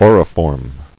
[au·ri·form]